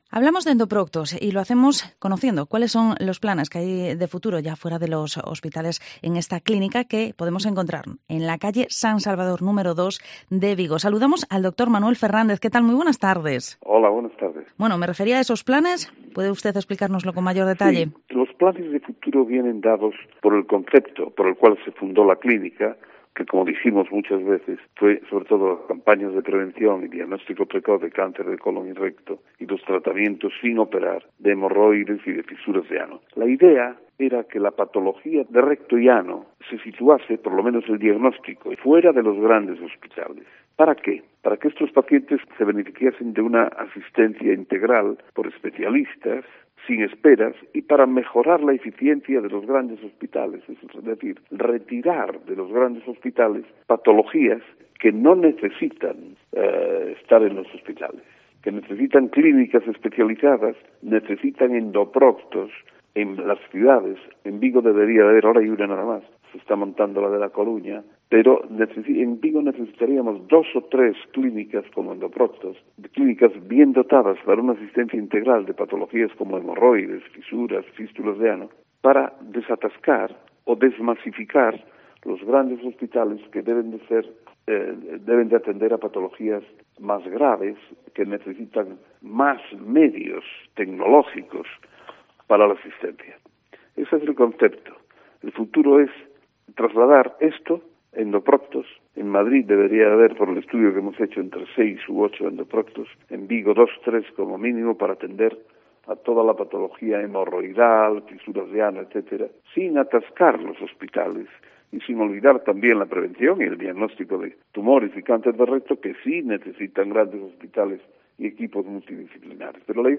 Vigo Entrevista ¿Cuál es el futuro de las clínicas como Endoproctos?